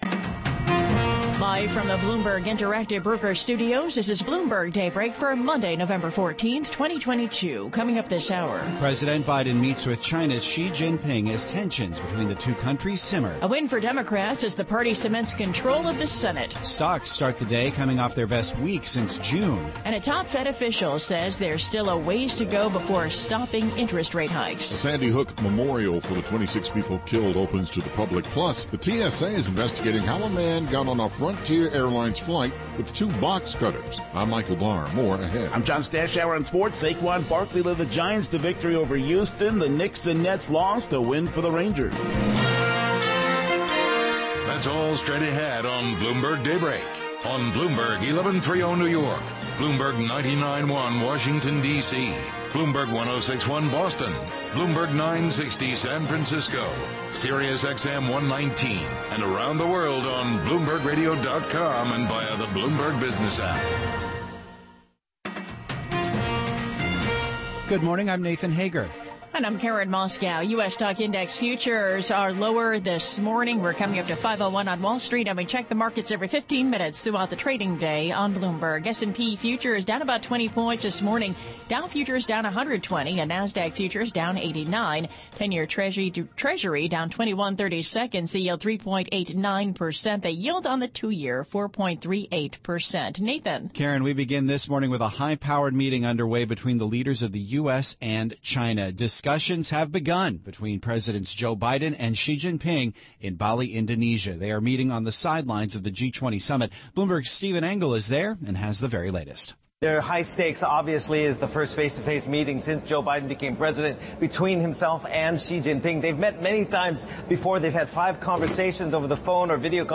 Bloomberg Daybreak: November 14, 2022 - Hour 1 (Radio)